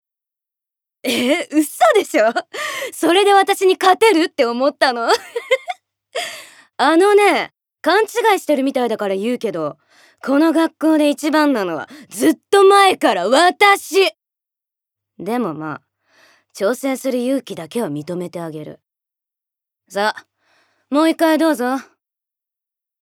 ボイスサンプル
セリフ２